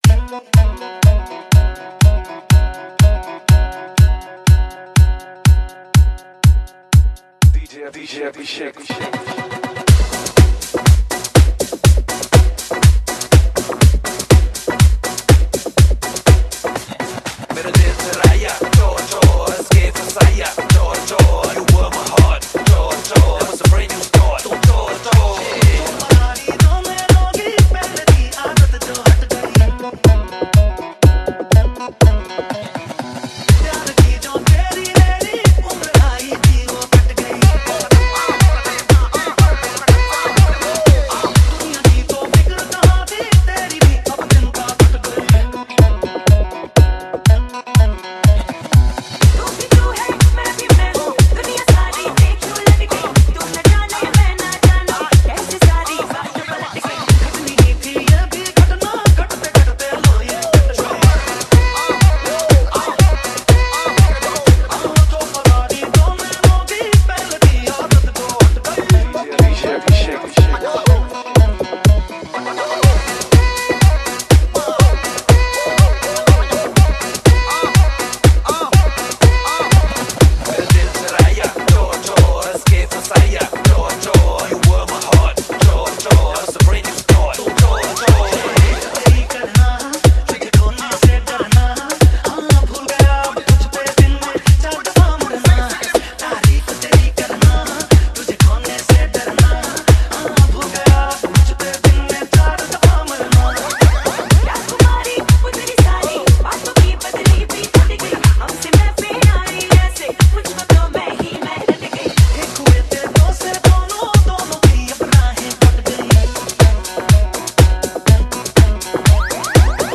Bollywood Dj Remix